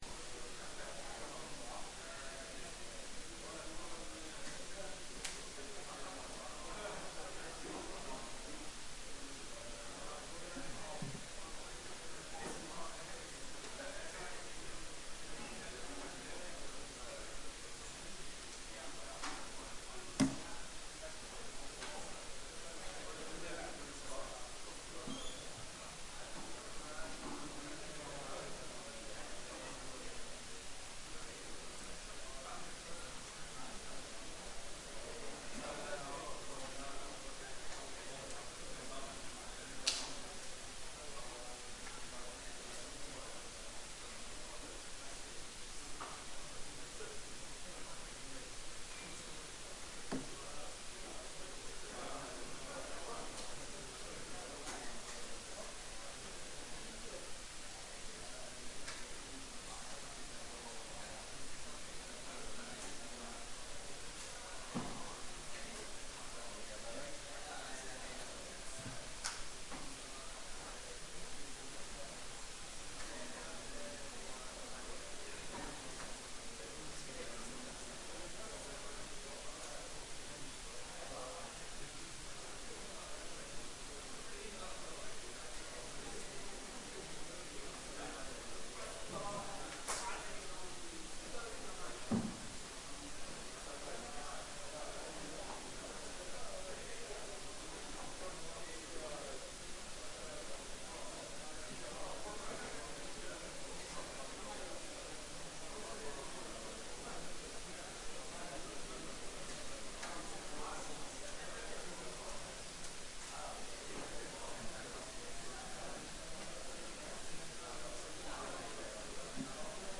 Kjemiforelesning 1
Rom: Store Eureka, 2/3 Eureka